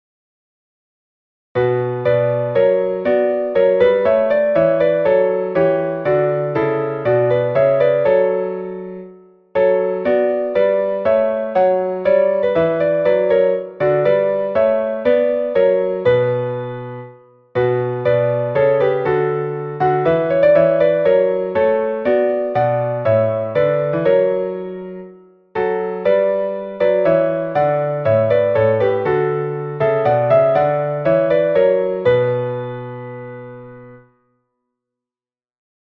Meter: 8.6.8.6
Key: b minor